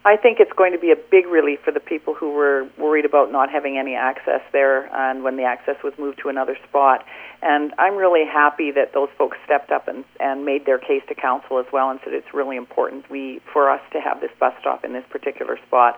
Mayor Deb Kozak says they listened to people who wanted to keep it near the supermarket.